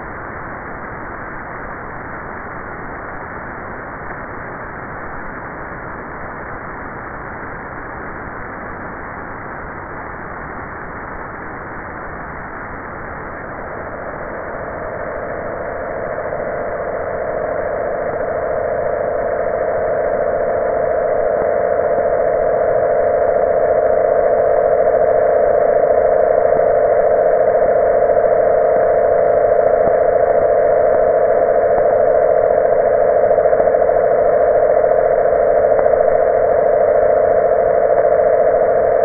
In the beginning you will hear the sound of the star based upon the measurements from the Kepler space telescope, speeded up by a factor of 250.000 to make it audible to a normal human ear. Within the roaring noise of the stellar matter, the whole star oscillates in a set of unique tones. The oscillations of the star, or starquakes, are then slowly amplified to stand out from the noise of the rest the star.